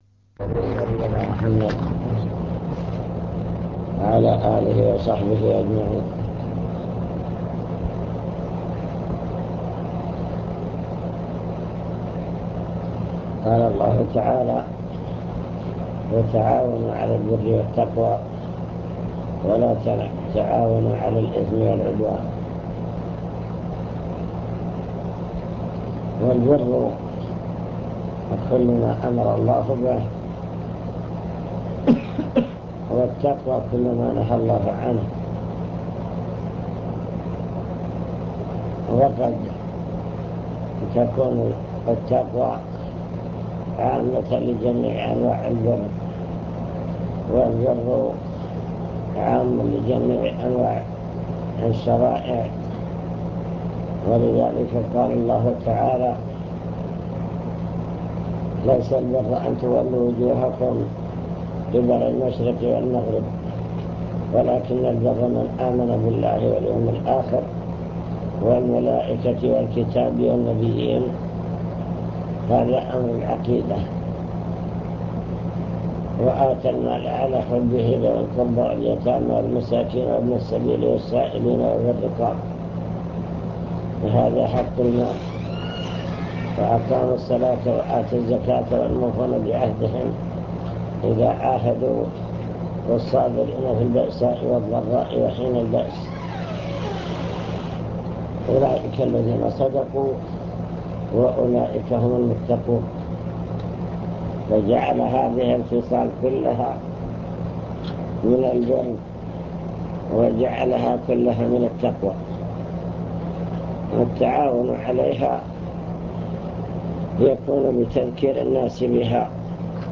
المكتبة الصوتية  تسجيلات - لقاءات  كلمة في الهيئة